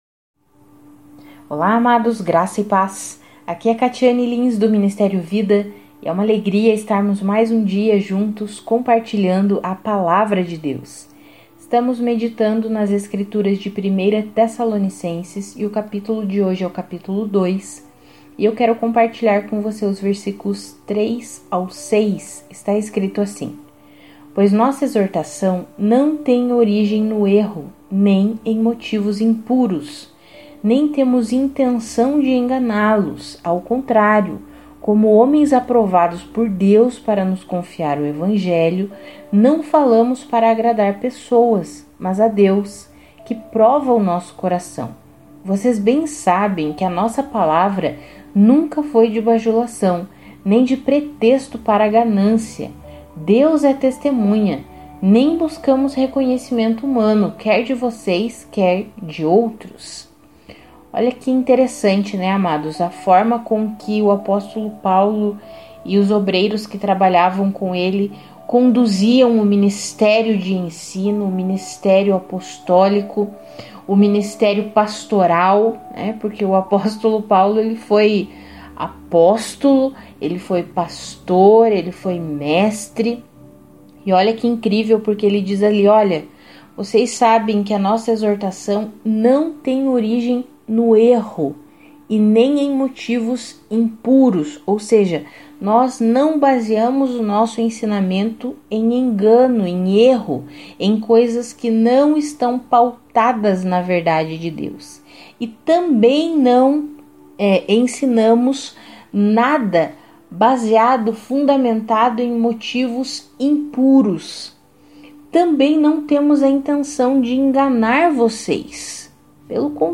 Além da leitura Bíblica nós fornecemos um devocional escrito e um devocional em áudio, todos os dias, para edificar a sua fé.